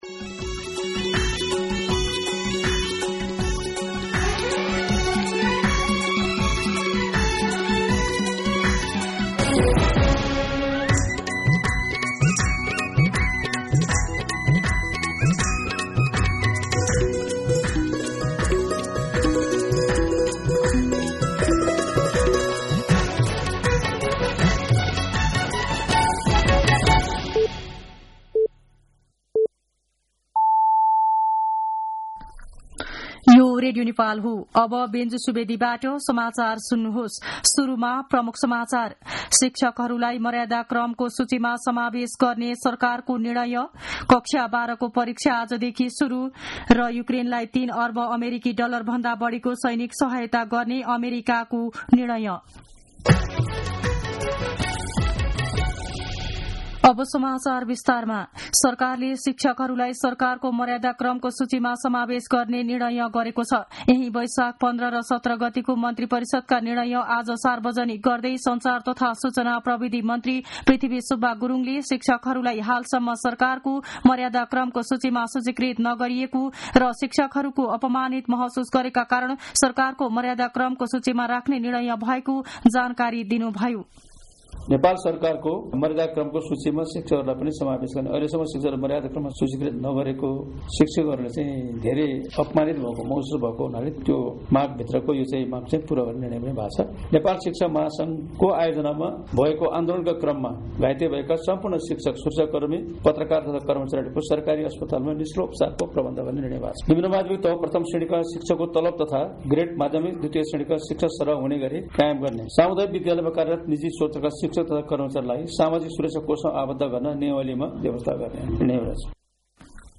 दिउँसो ३ बजेको नेपाली समाचार : २१ वैशाख , २०८२
3-pm-Nepali-News.mp3